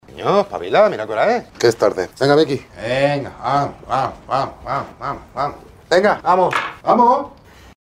despertador-luisito-rey-te-despierta_RVRJ9ZV.mp3